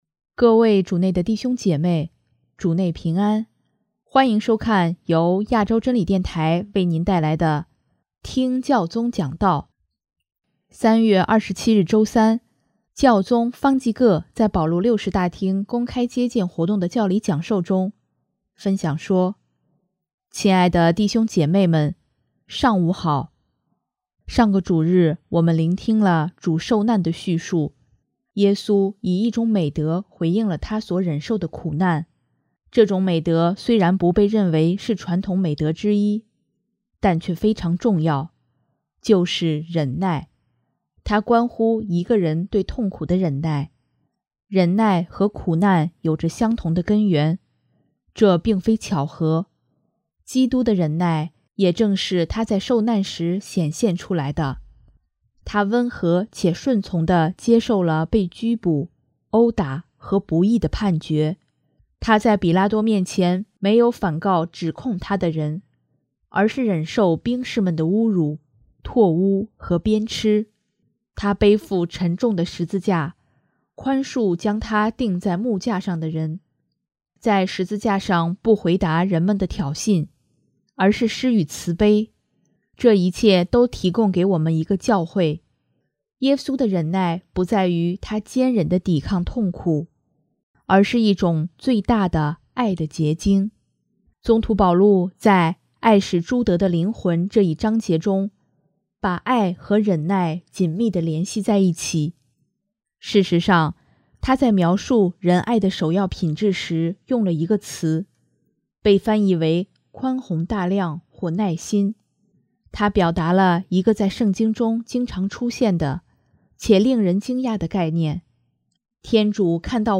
3月27日周三，教宗方济各在保禄六世大厅公开接见活动的教理讲授中，分享说：